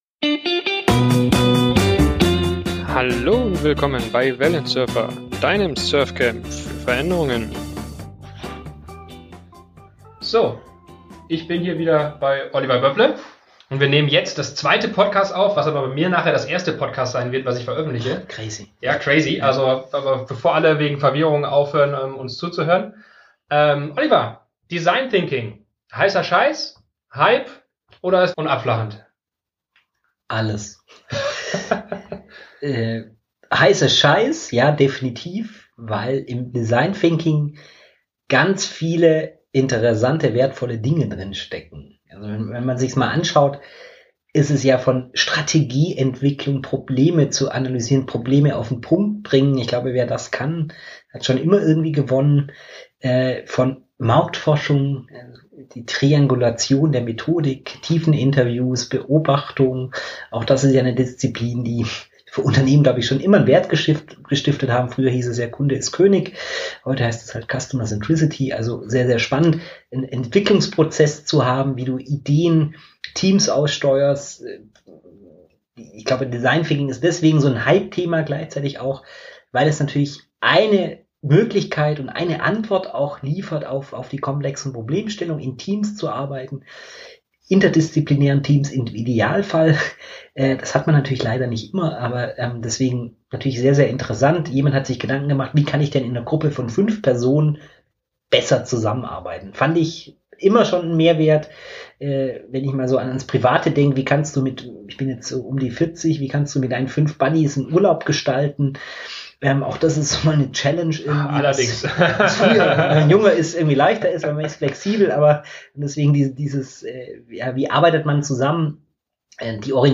erläutere ich im Gespräch mit